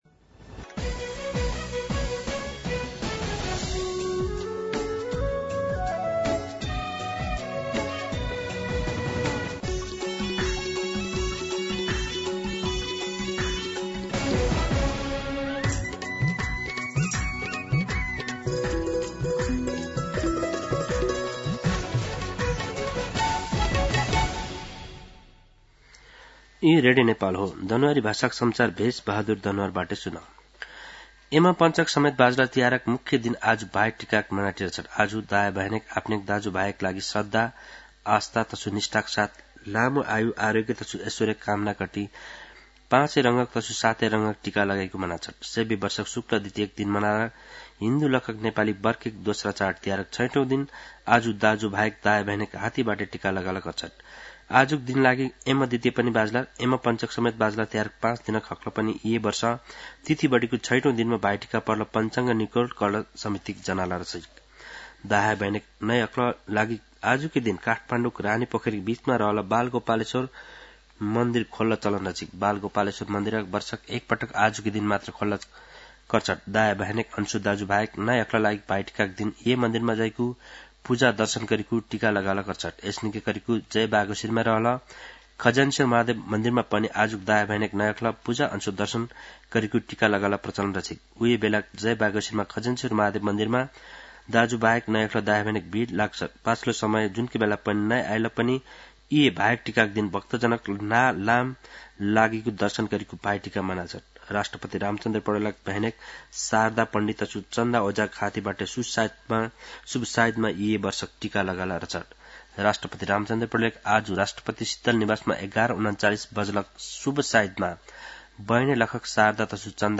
दनुवार भाषामा समाचार : ६ कार्तिक , २०८२
Danuwar-News-8.mp3